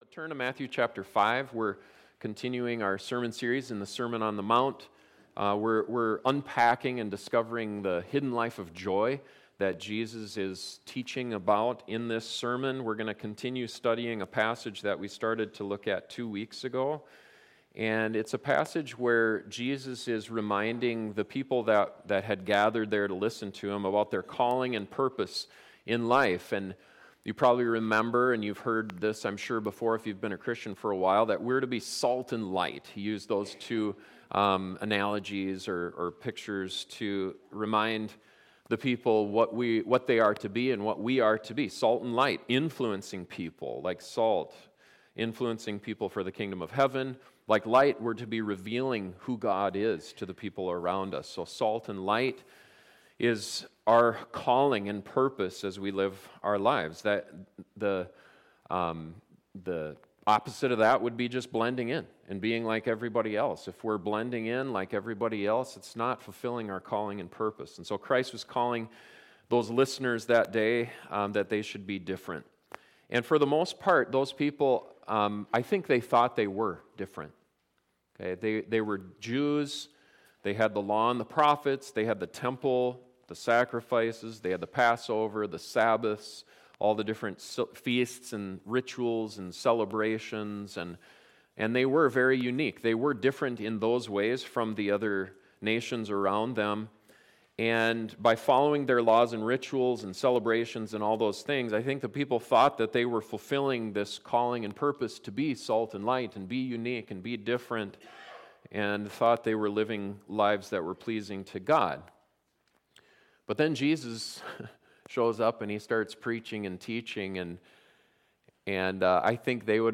This sermon looks at some specific examples […]